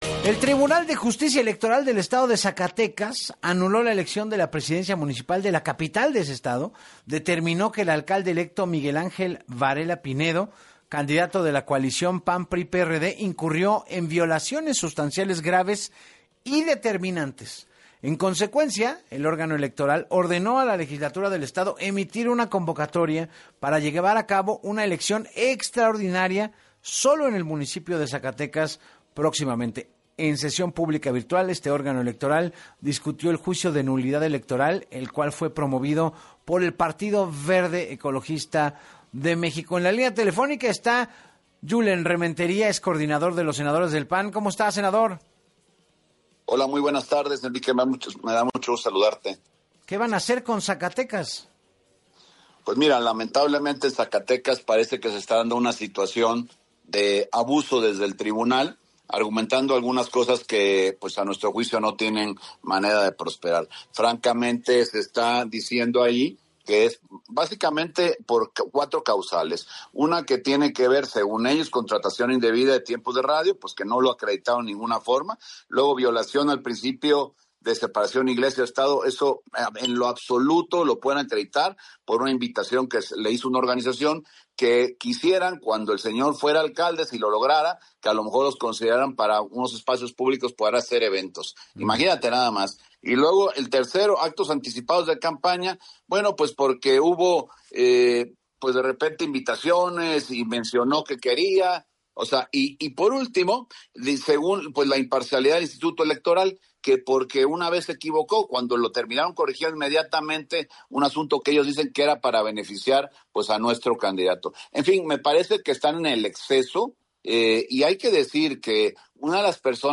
En entrevista con Enrique Hernández Alcázar, el senador y coordinador del Partido Acción Nacional (PAN), Julen Rementería, habló sobre la anulación de la elección municipal de Zacatecas y dijo que es una situación de abuso desde el Tribunal “argumentan cosas que no tienen manera de prosperar… me parece que están en el exceso”, levanta sospecha la relación de la comadre de David Monreal, la magistrada presidenta, Magda Gloria Esparza quien dio el voto de calidad al ser aprobada con dos a favor y dos en contra.